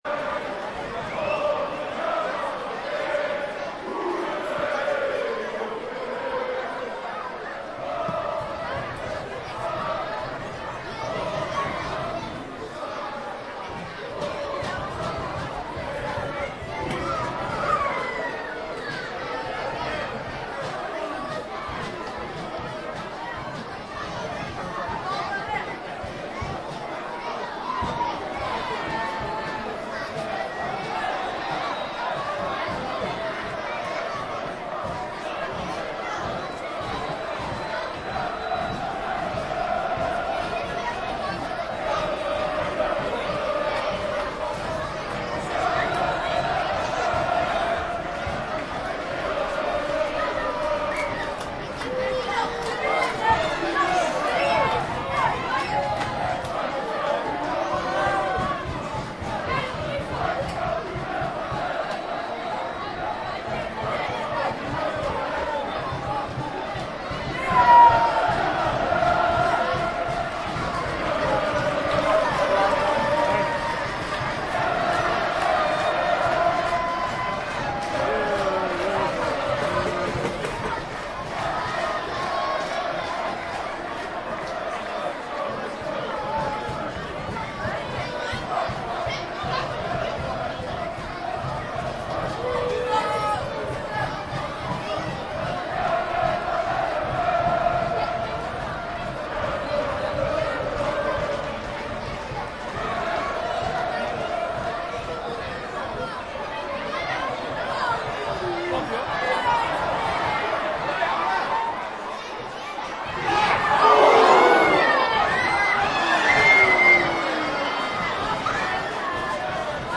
soccer-stadium.mp3